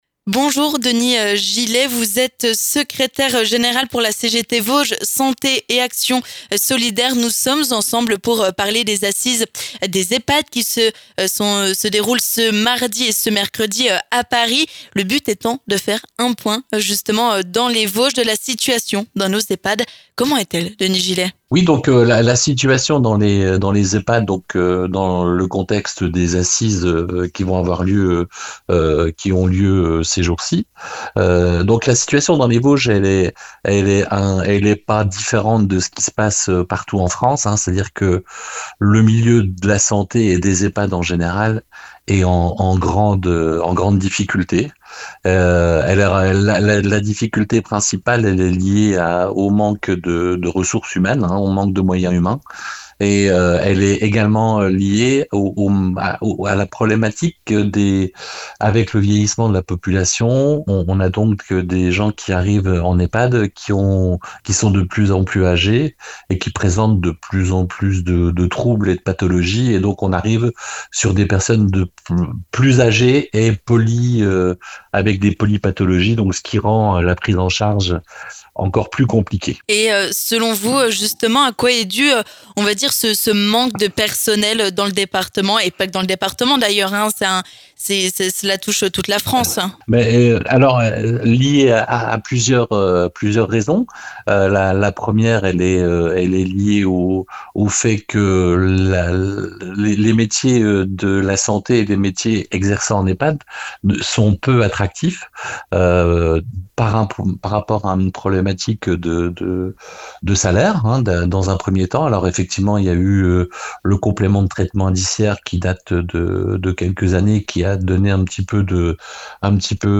L'occasion pour Vosges FM d'interroger les syndicats vosgiens pour faire un état des lieux de la situation de nos maisons de rretraite, dans le département. Et à l'image du reste de la France : les établissements manquent de bras et de moyens pour mener à bien leurs missions de soin auprès des résidents.